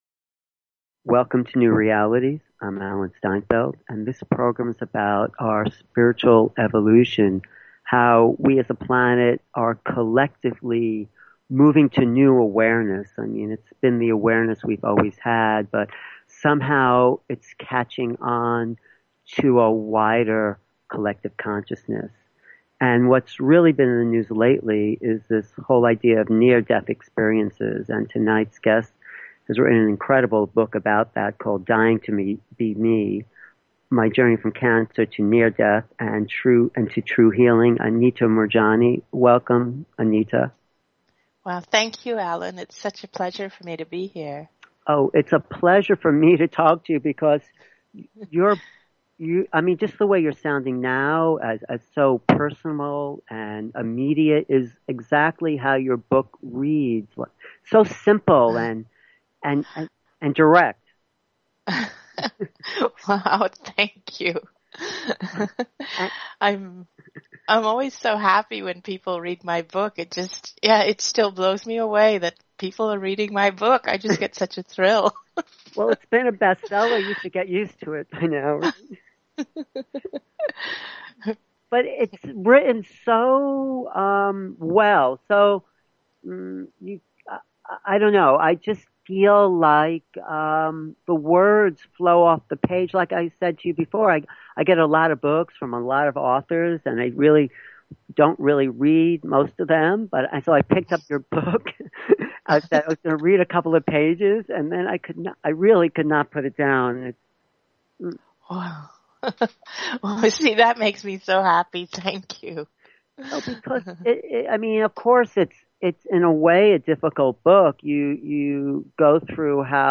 Interview with Anita Moorjani, Dying to Be Me. NDE changes life and heals fatal disease..